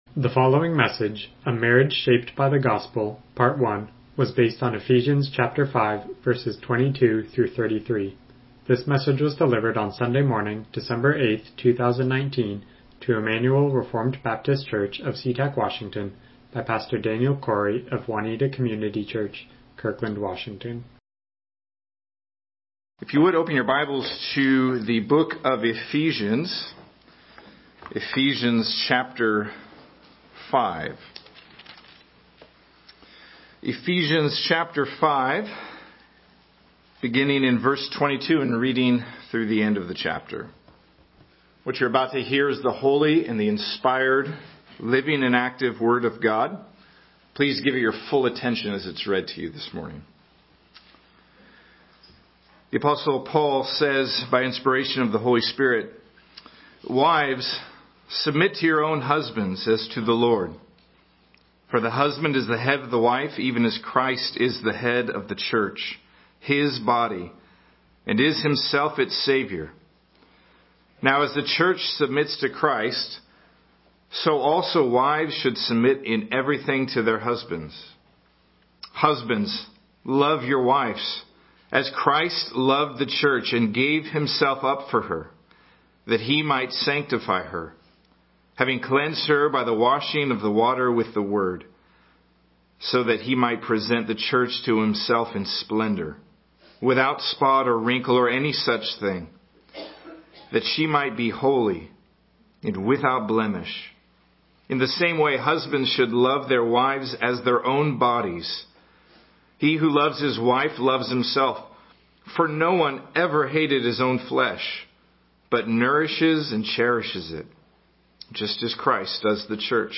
Passage: Ephesians 5:22-33 Service Type: Morning Worship